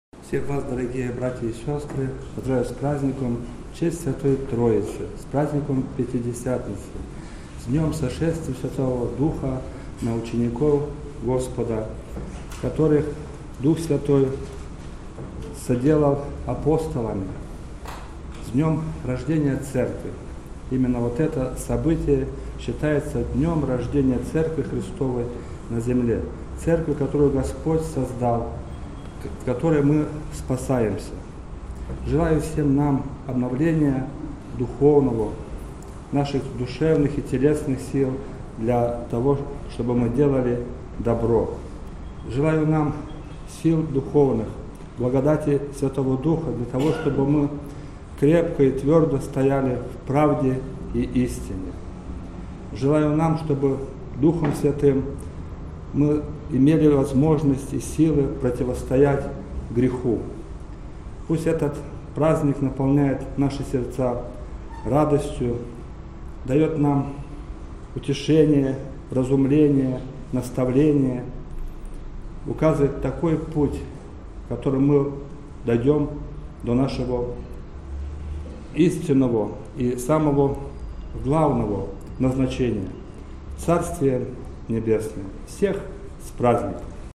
Проповедь
Троица.-Вечернее-богослужение.mp3